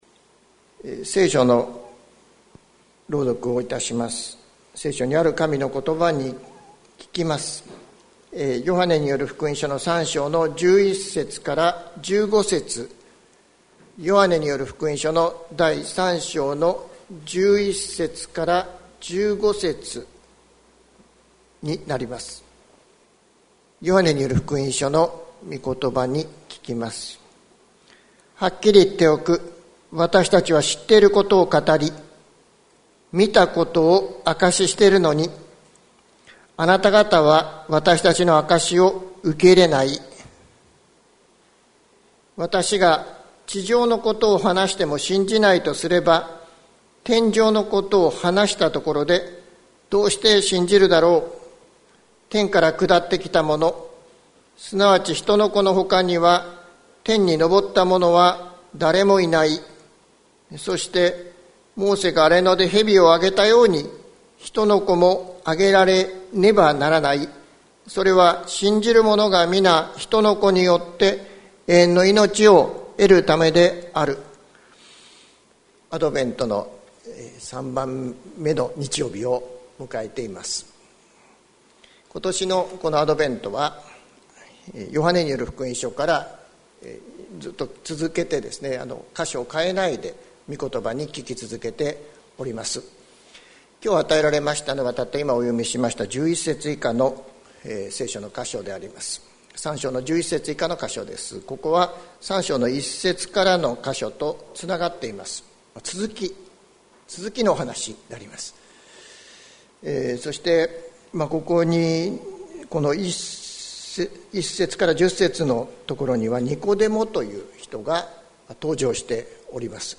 2021年12月13日朝の礼拝「信じて、仰ぎ見る」関キリスト教会
説教アーカイブ。